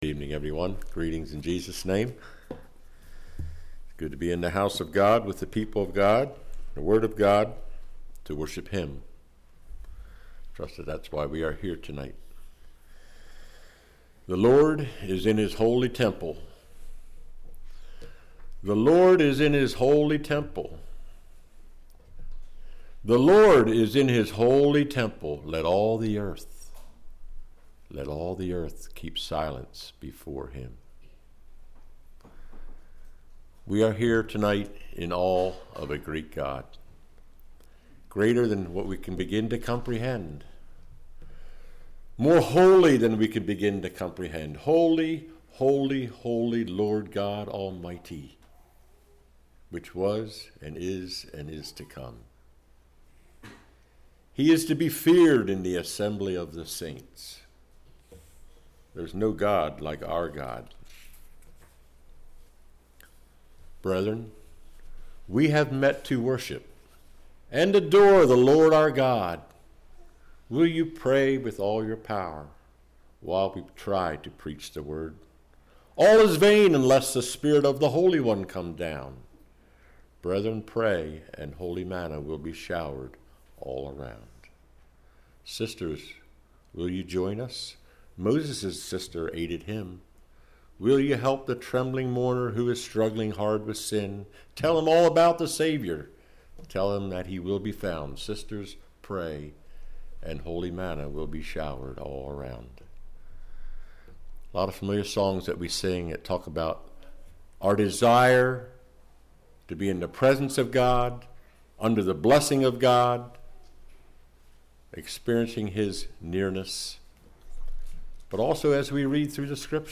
Congregation: Elm Street